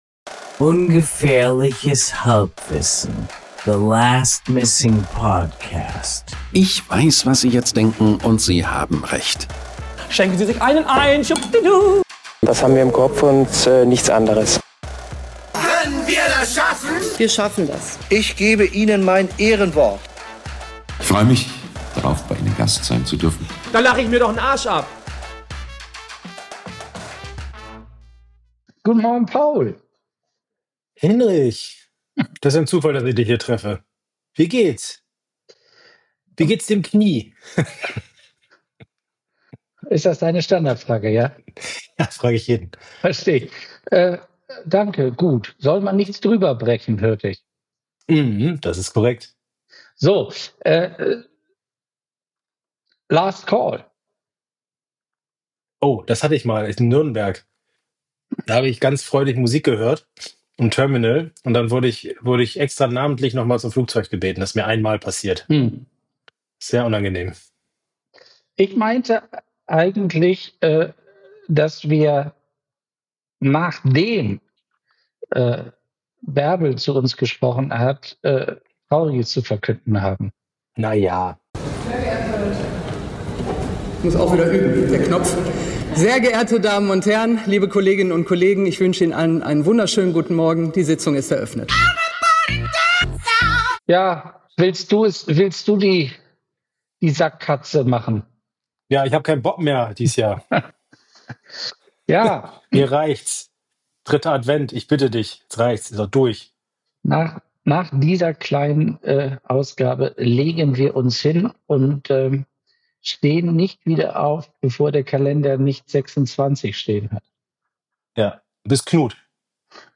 Ungefährliches Halbwissen – The Last Missing Podcast Ungefährliches Halbwissen ist der Laberpodcast de luxe, bei dem Abschweifen zur Königsdisziplin erhoben wird.
An guten Tagen ist das Hören von Ungefährliches Halbwissen wie das Belauschen eines unterhaltsamen, bisweilen anspruchsvollen, fast immer respektvollen und gelegentlich sogar informativen Gesprächs auf einer Party.